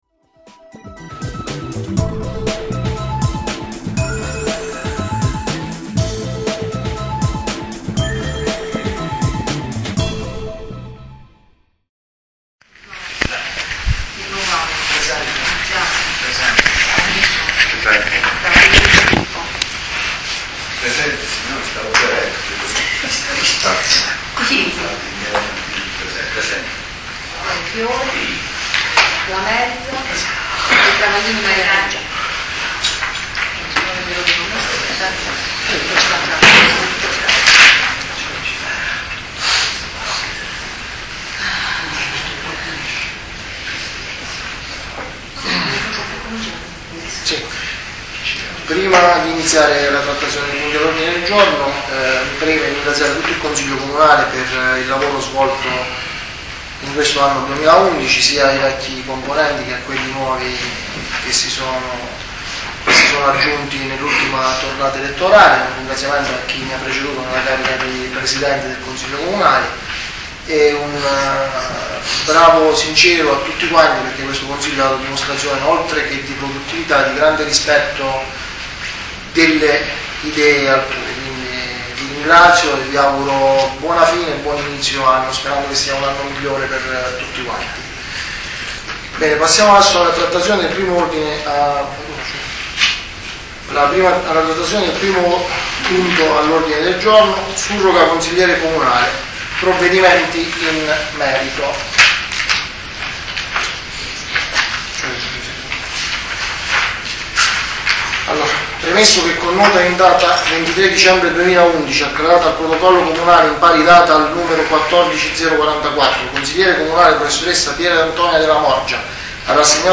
Ascolta il Consiglio Comunale del 29 Dicembre 2011